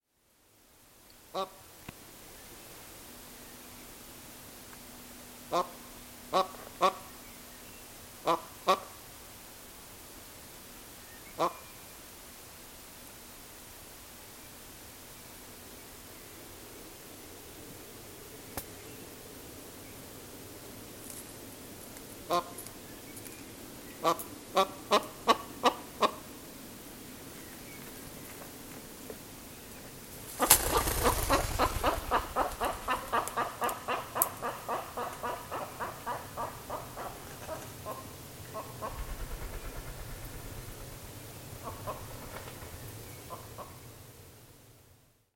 Звуки глухаря
Еще одна самка глухаря